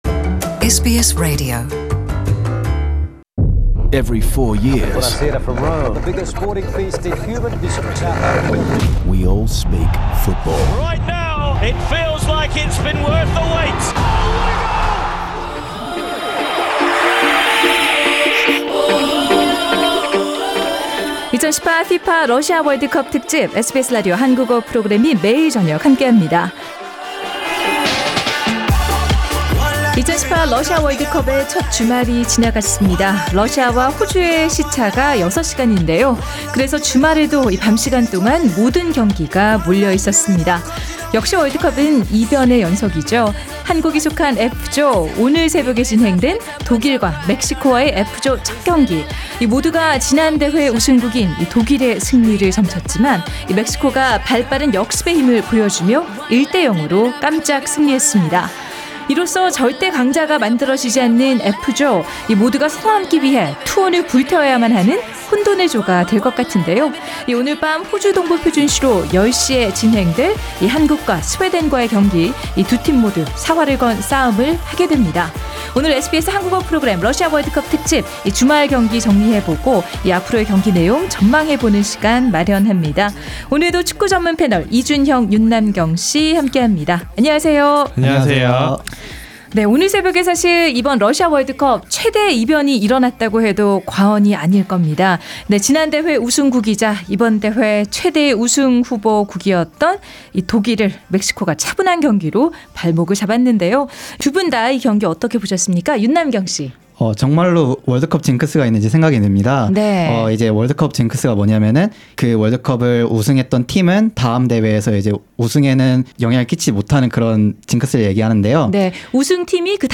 SBS Korean program's Russia World Cup special today examine Mexico v Germany match and predict the next game of the group, Republic of Korea v Sweden. The World Cup panel of two in Sydney